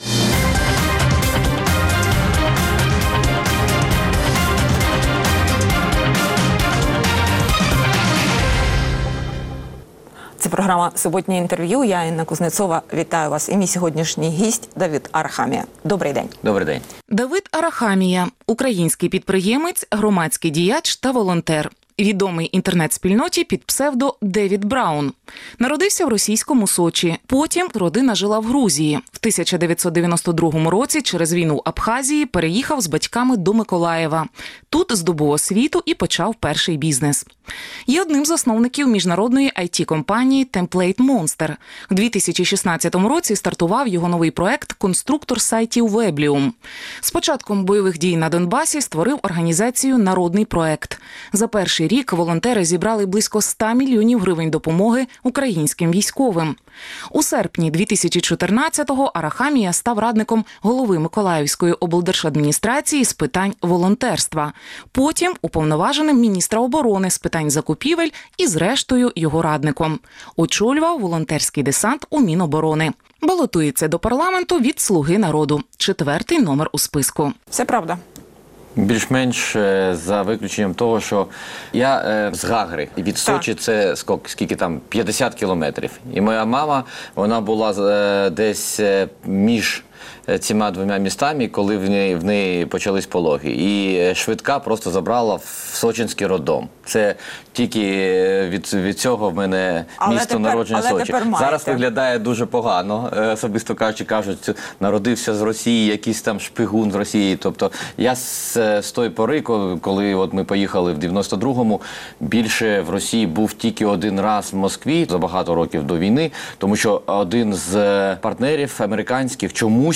Суботнє інтерв’ю | Давид Арахамія, підприємець і волонтер
Суботнє інтвер’ю - розмова про актуальні проблеми тижня. Гість відповідає, в першу чергу, на запитання друзів Радіо Свобода у Фейсбуці